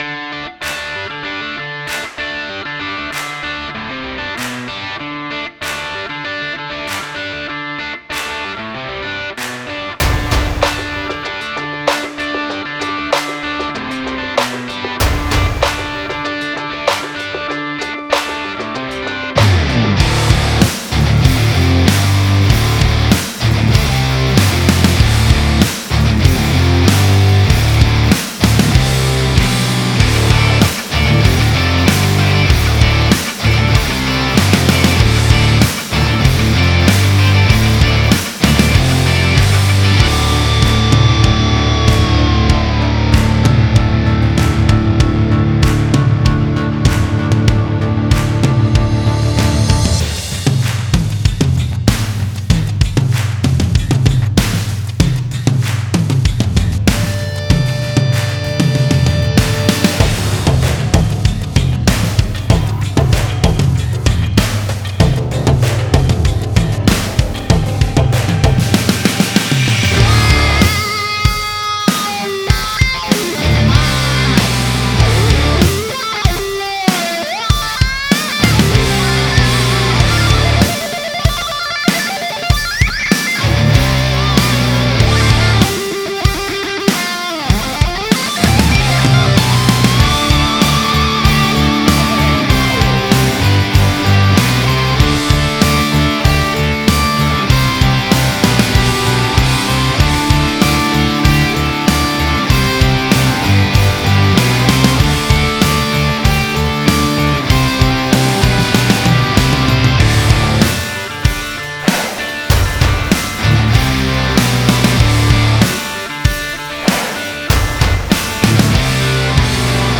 Перегруженный звук
тоже все сведено ITB ) тоже плагины вместо усилителей